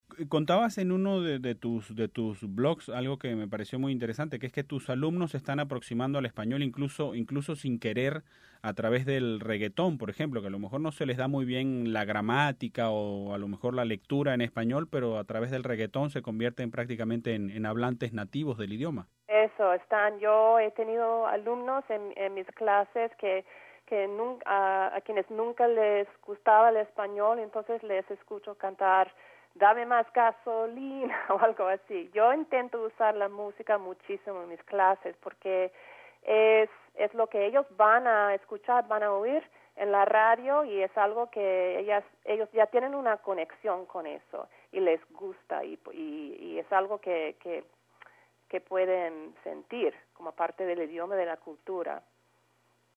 Escúchenlo contado por ella misma. Bueno, o más bien cantado por ella misma.